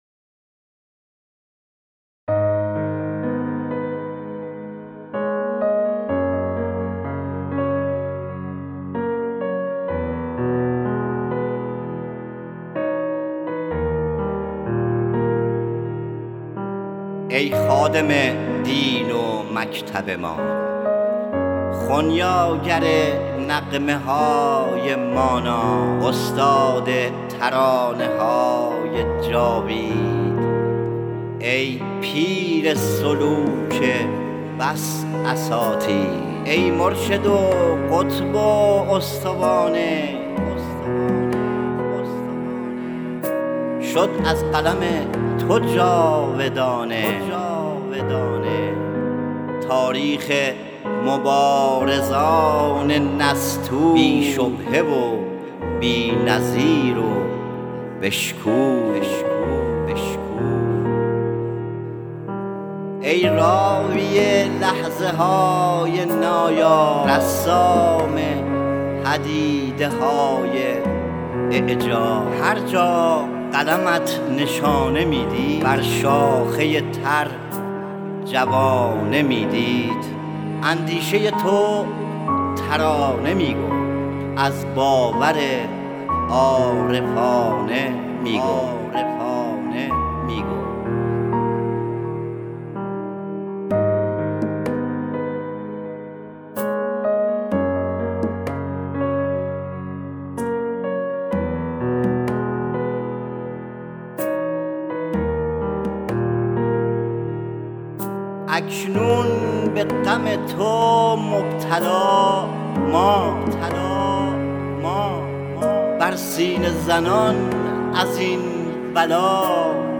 بر مبنای مثنوی و صدای شاعر انقلاب